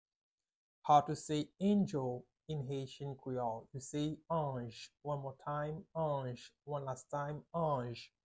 "Angel" in Haitian Creole is "anj" - "Anj" pronunciation by a native Haitian tutor
“Anj” Pronunciation in Haitian Creole by a native Haitian can be heard in the audio here or in the video below:
How-to-say-Angel-in-Haitian-Creole-Anj-pronunciation-by-a-native-Haitian-tutor.mp3